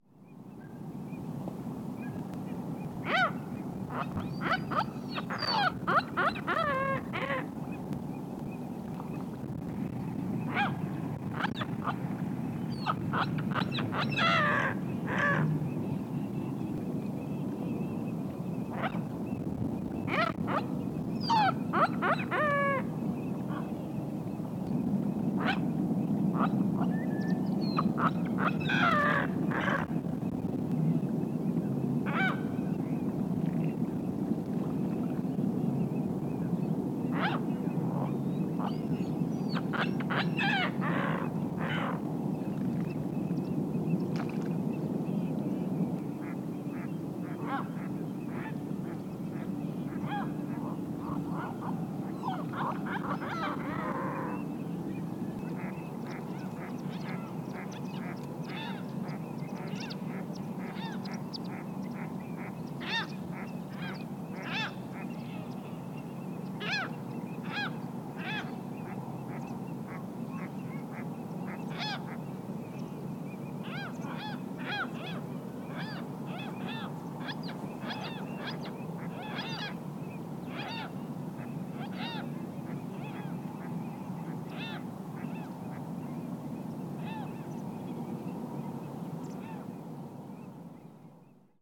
Relict Gulls on spring migration
Last week I was fortunate to encounter a flock of around 65 on the shores of Guanting Reservoir and they treated me to a spectacular show.
Here, a few started to display, throwing back their heads and almost sounding as if they were in pain!
The recording below includes some courtship calls, followed by some flight calls.